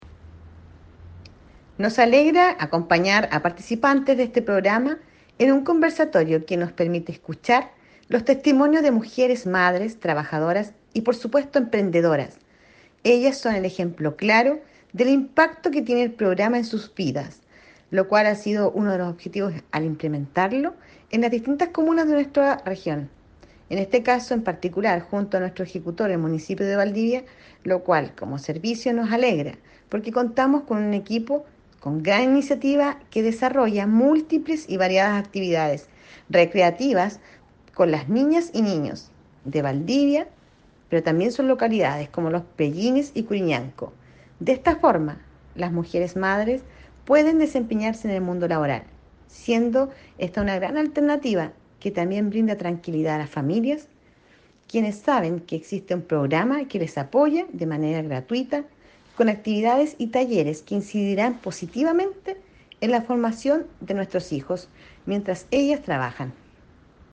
cuña-DIRECTORA-SERNAMEG-WALESKA-FERHMANN-ATERO.mp3